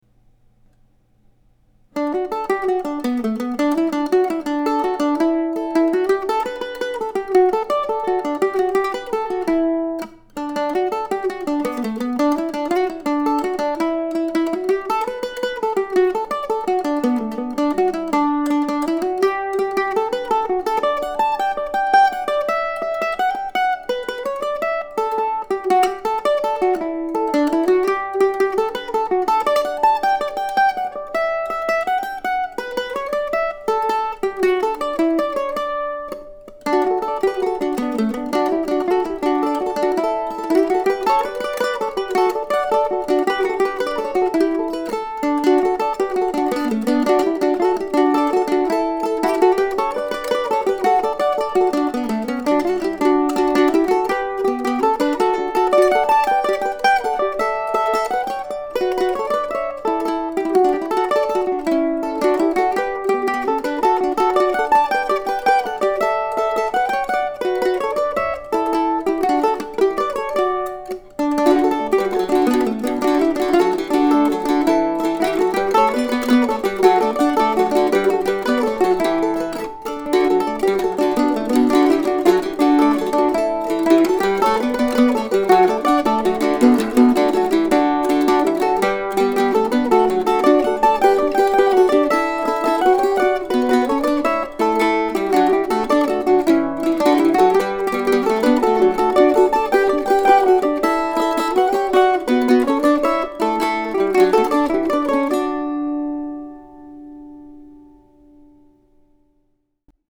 This friendly jig came to me a few weeks ago and I thought of this title while looking at online maps of the area just to the west of New Albany, Indiana.